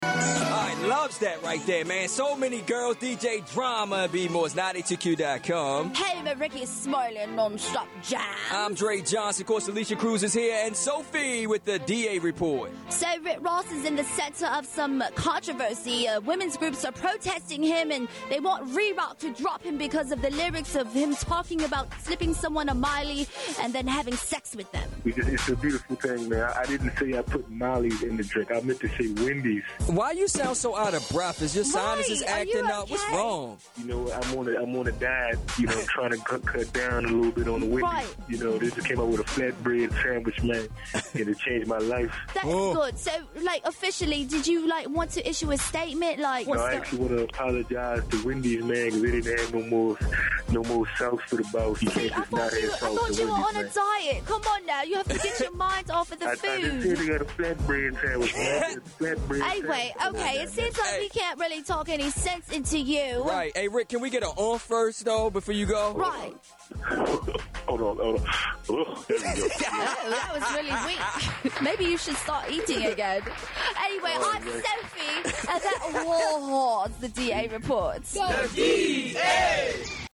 Rick Ross Speaks on Rape Lyric Accusations